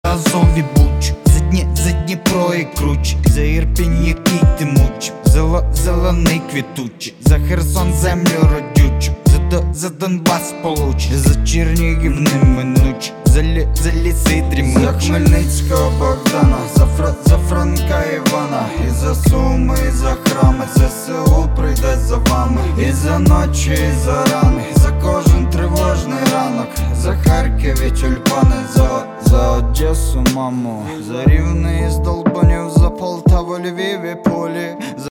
• Качество: 192, Stereo
мужской голос
спокойные